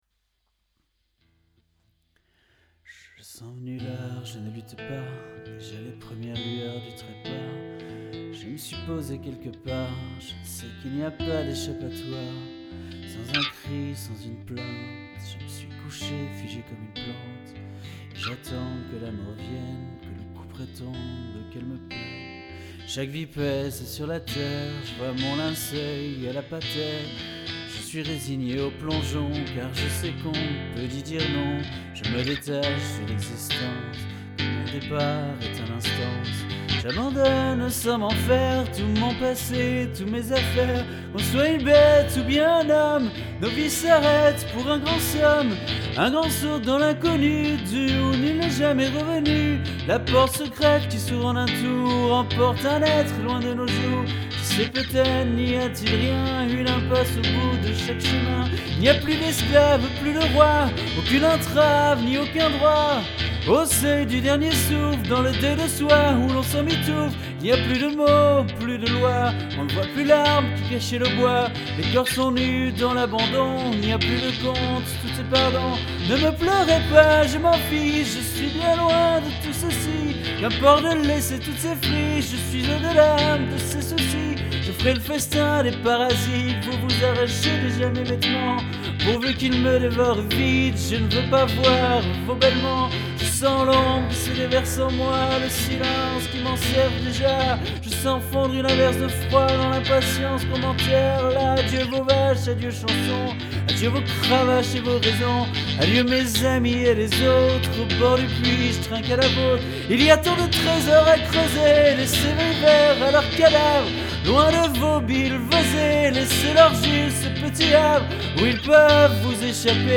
La voix est horrible, la guitare à refaire totalement, et le crescendo serait à travailler avec d’autres instruments pour donner plus de force et de profondeur en même temps à la chanson, jusqu’à la retombée finale…
• Voix
• Guitare